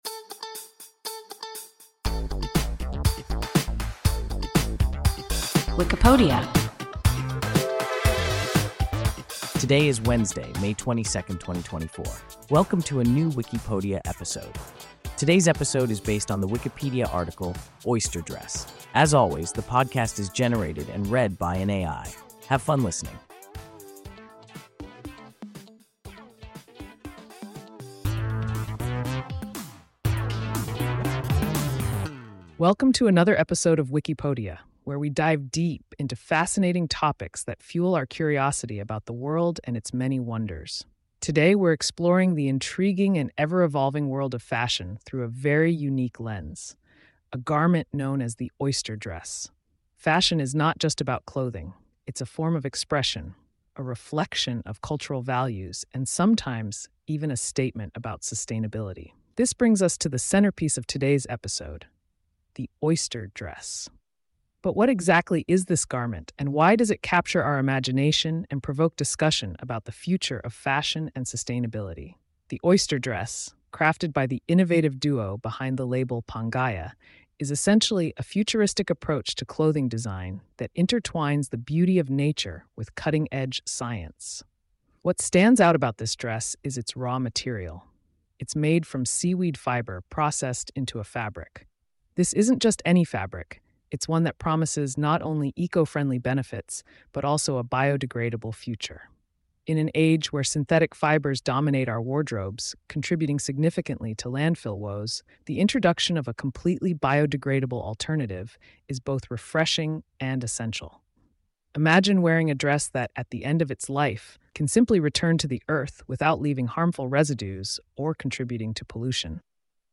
Oyster dress – WIKIPODIA – ein KI Podcast